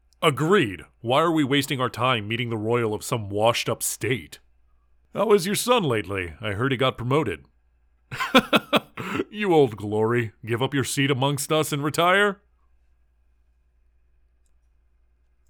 Voice: Deep, older sounding